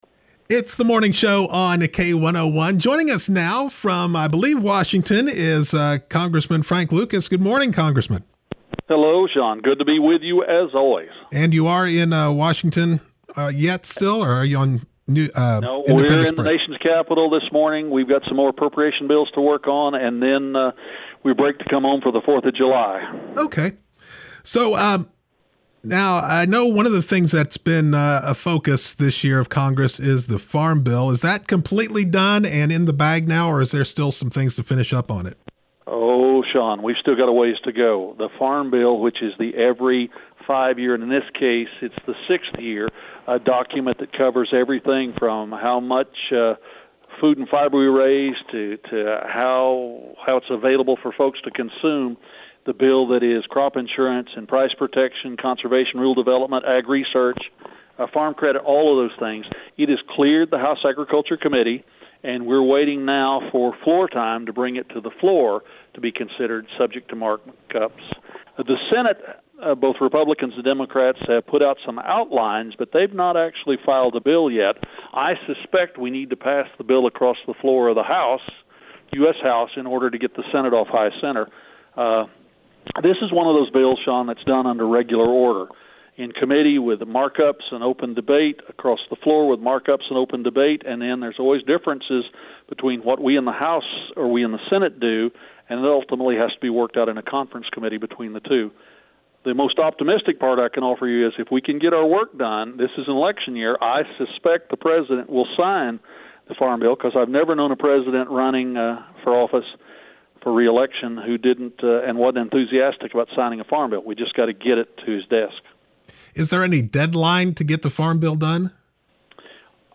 Oklahoma 3rd District Congressman Frank Lucas was on the K-1O1 Morning Show Friday, June 28th to give an update on the new Farm Bill, some appropriations bills before Congress, his primary election bid and goals for his next term as Congressman.